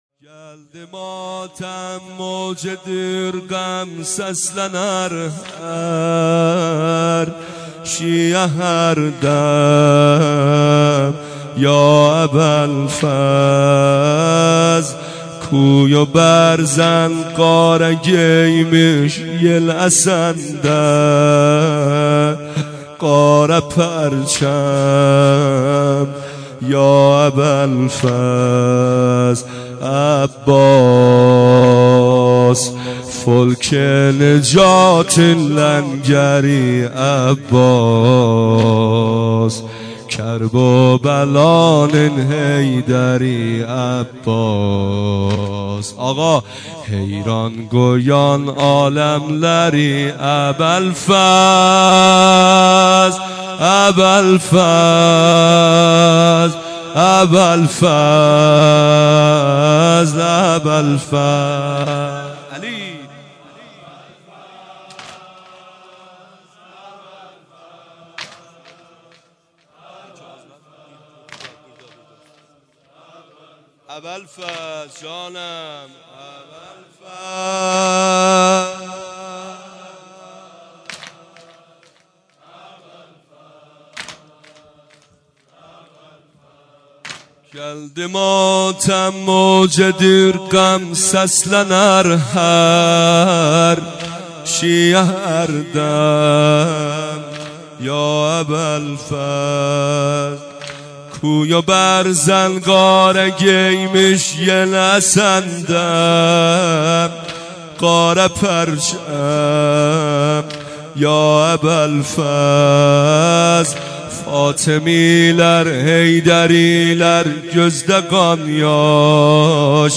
سینه سنگین | گلدی ماتم
سینه زنی سنگین مداحی